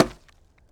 Bubberstation/sound/effects/treechop/treechop2.ogg
treechop2.ogg